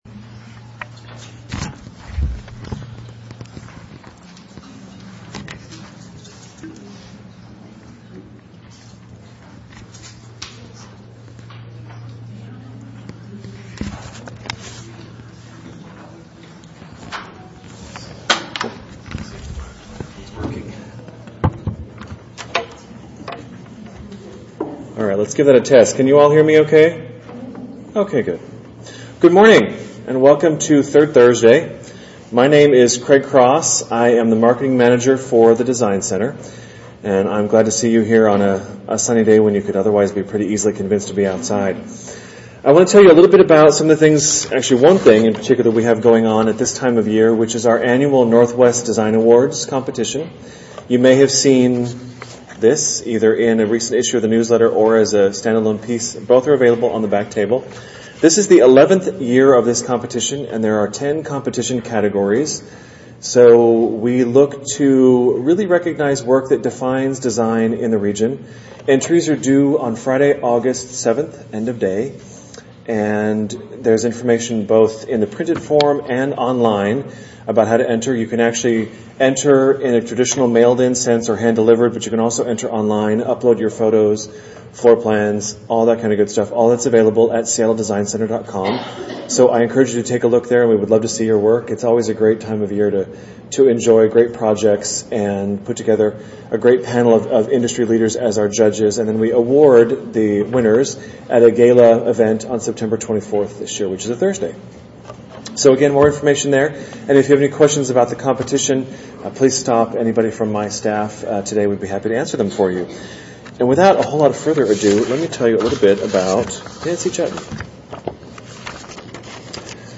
Here — for one week only — you can have access to the audio file of my one-hour talk that earned rave reviews at Seattle Design Center last week.